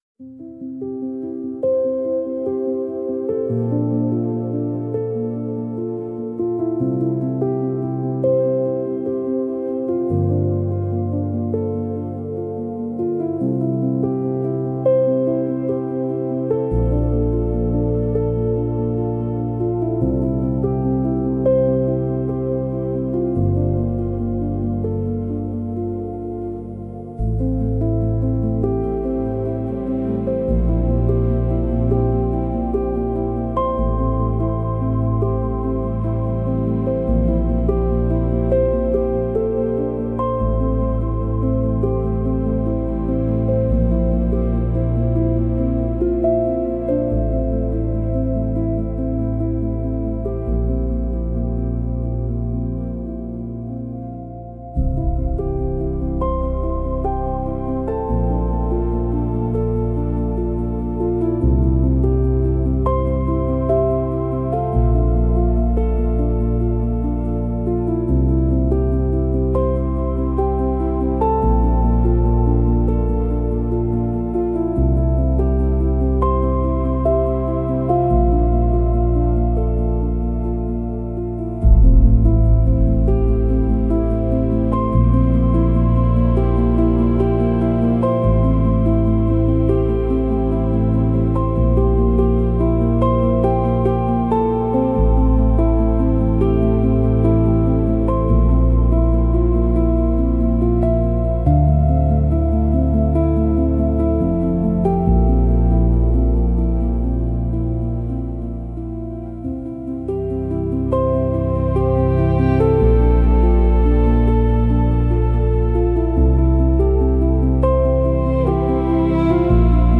Ambient, Hang Drum, Soundtrack, Emotive, Melancholic, Sad